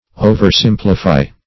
oversimplify - definition of oversimplify - synonyms, pronunciation, spelling from Free Dictionary